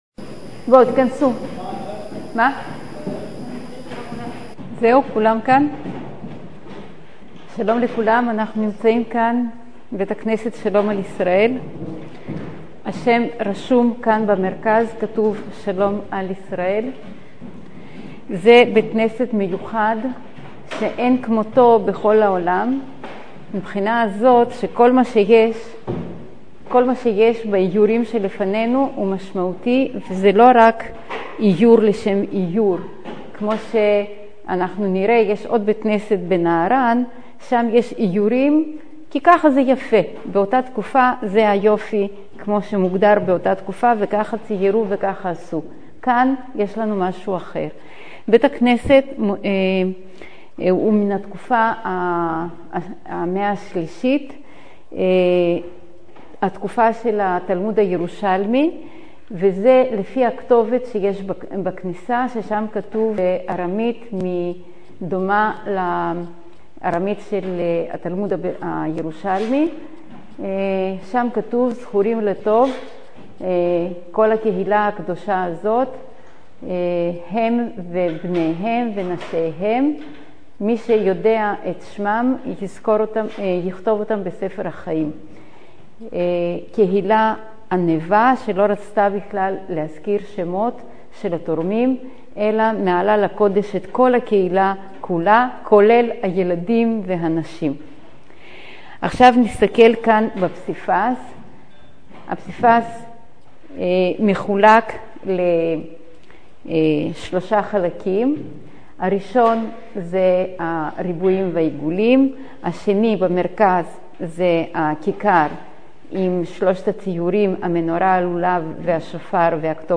סיור מודרך